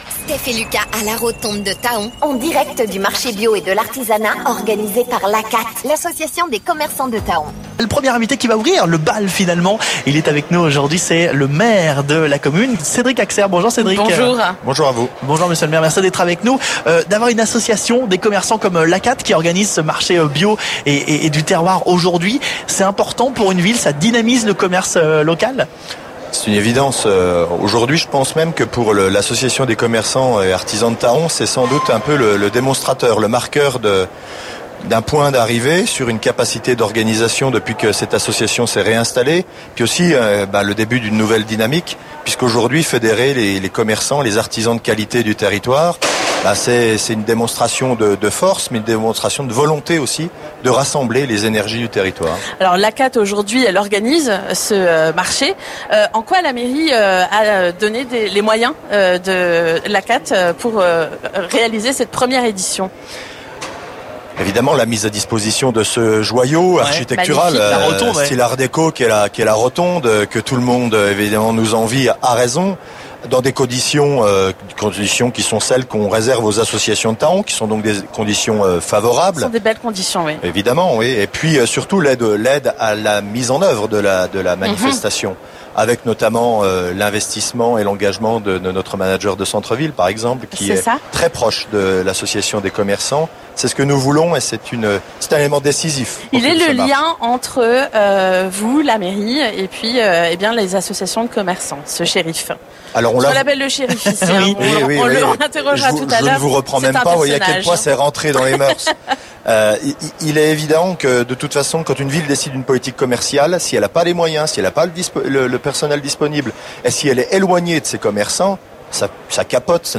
Revivez notre live au marché de l'ACATE à Thaon !
Et en plus, dans le magnifique décor qu'est la Rotonde !